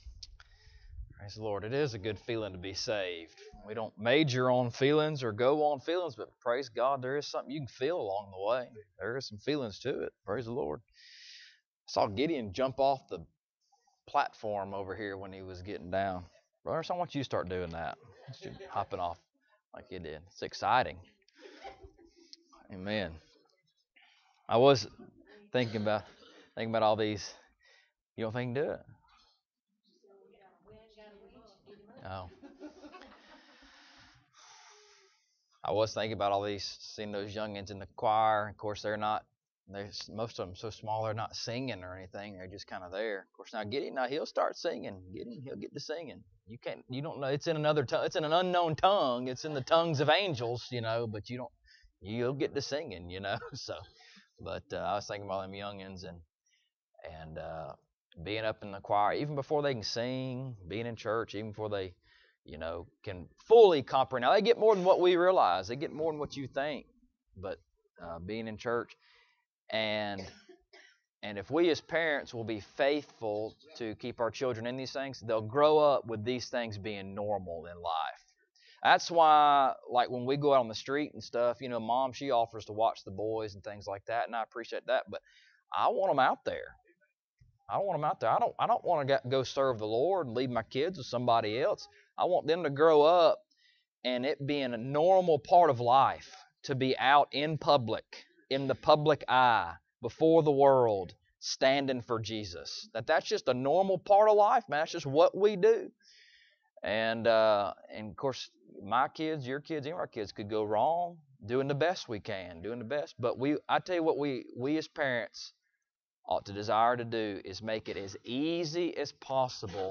Ephesians Passage: Ephesian 1: 1-14 Service Type: Sunday Morning Topics